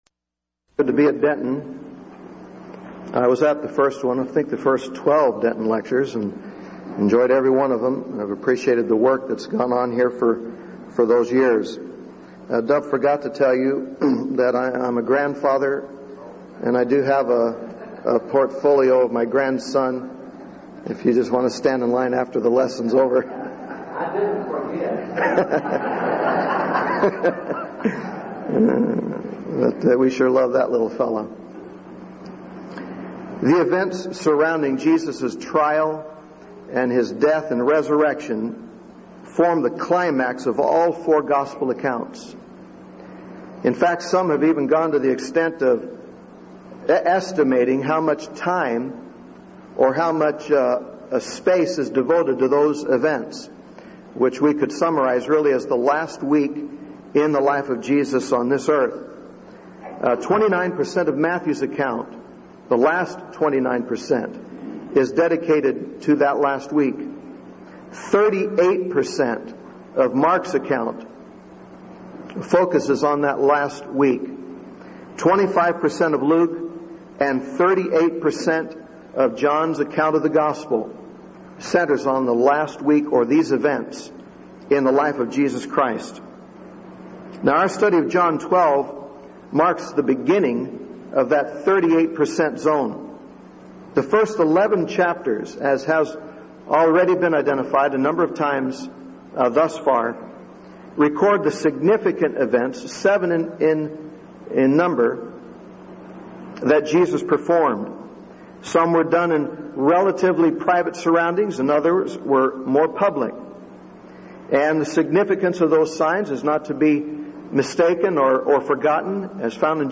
Event: 1999 Denton Lectures
lecture